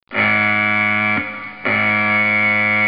Sirene_Loop_3s.wav